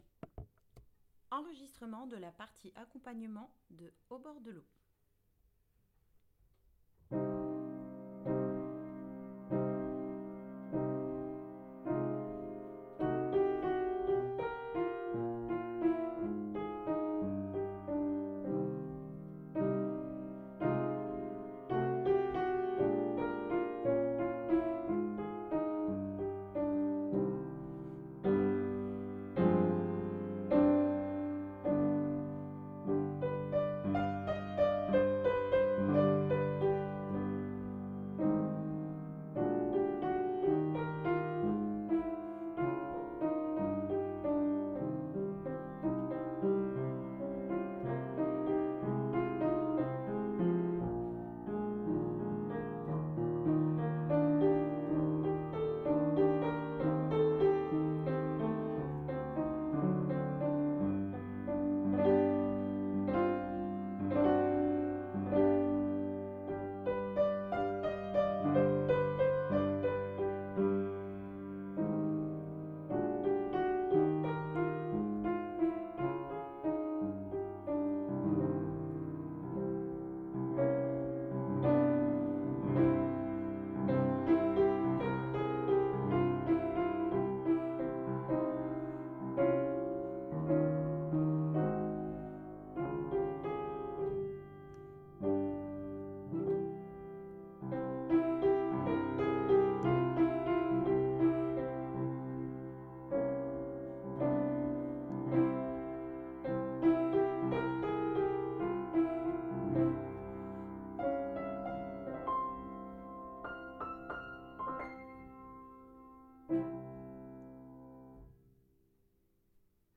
Accompagnement piano Se tu m'ami